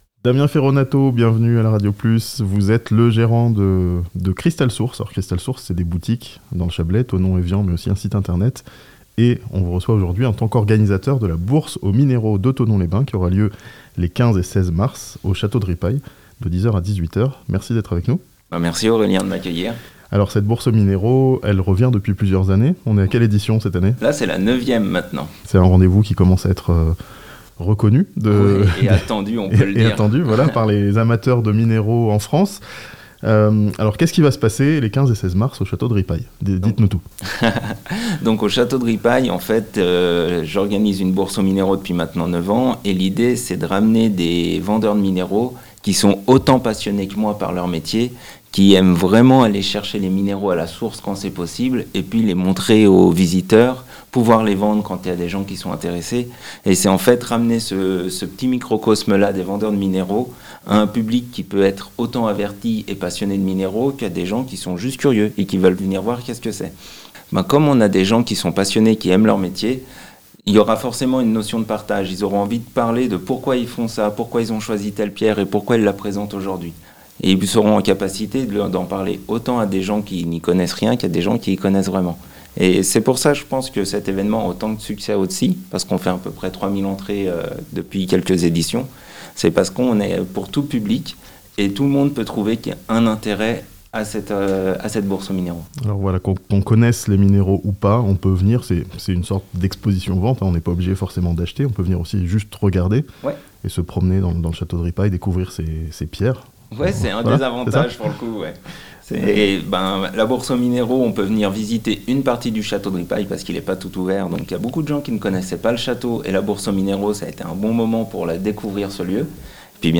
Une bourse aux minéraux au château de Ripaille, à Thonon (interview)